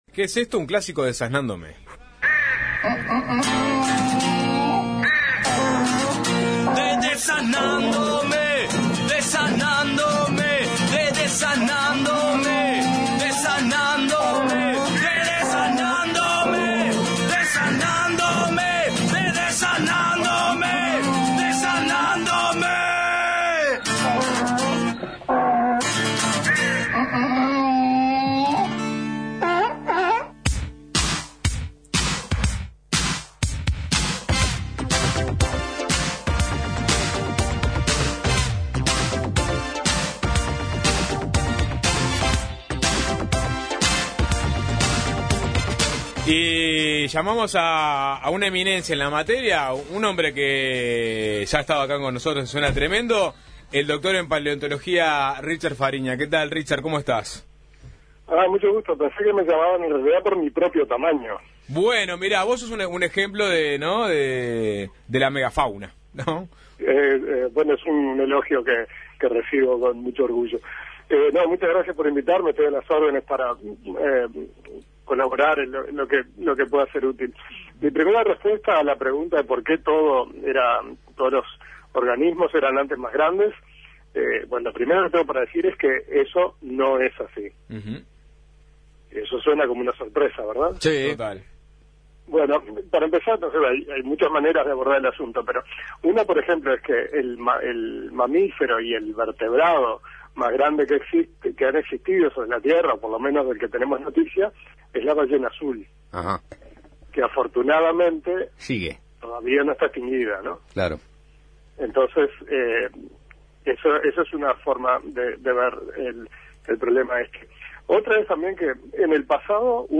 paleontólogo, estuvo conversando con Suena Tremendo.